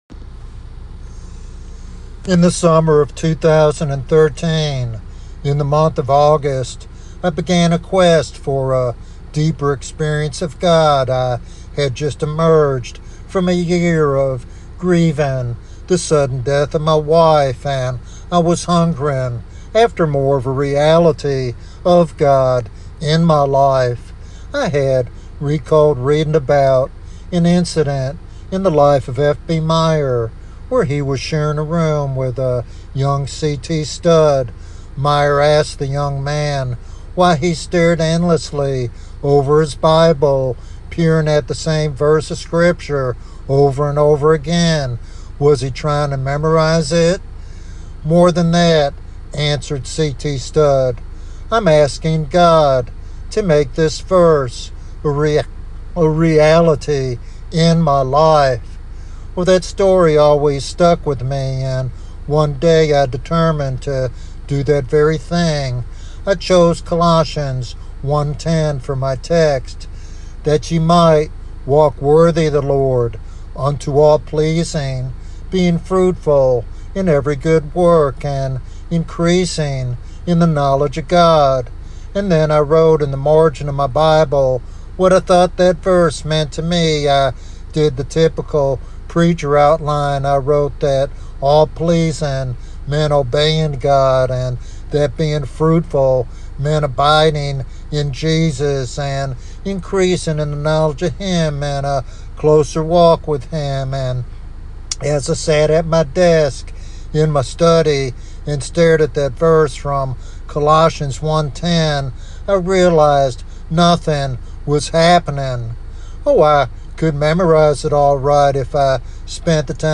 This devotional sermon encourages believers to pursue a deeper, authentic relationship with God that transcends superficial faith.